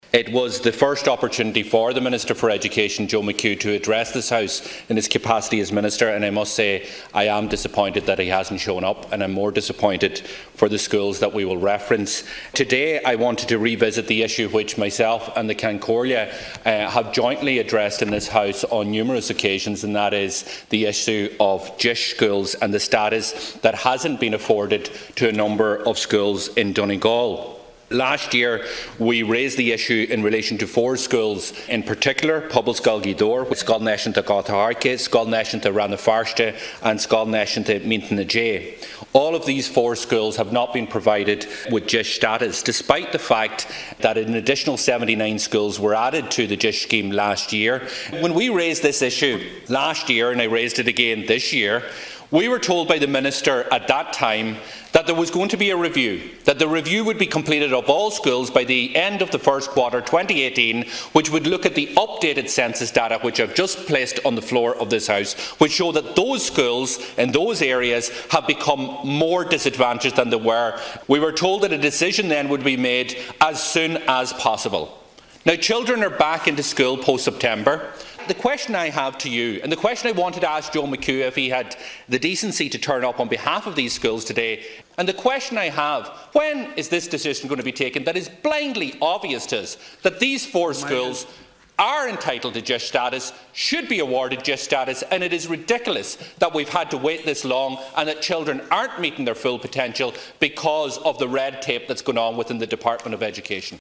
In the Dail a short time ago, Donegal Deputy Pearse Doherty lambasted Minister McHugh for failing to turn up today to discuss the need to extend the Deis scheme.
Deputy Doherty told Minister Mitchell O’Connor that the issue needs to be addressed without further delay: